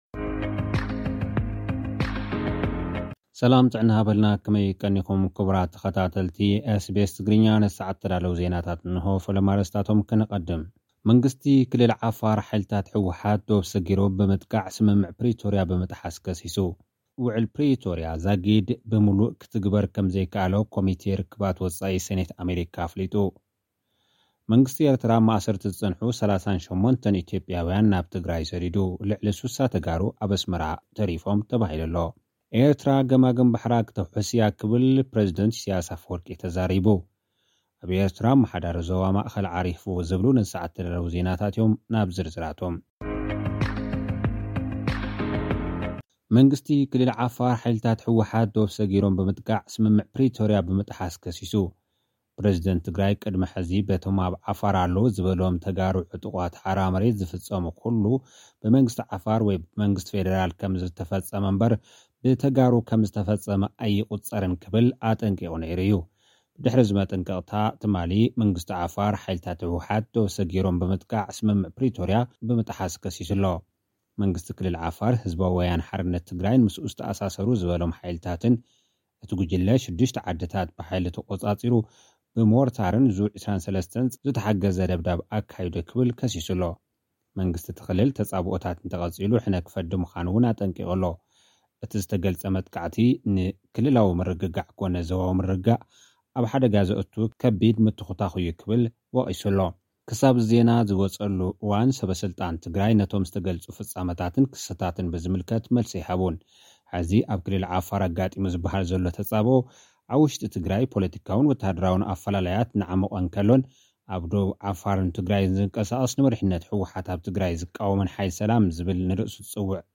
ኤርትራ ገማግም ባሕራ ከተውሕስ ትኽእል እያ፡ ክብል ፕረዚደንት ኢሳይያስ ኣፈወርቂ፡ ተዛሪቡ። (ጸብጻብ)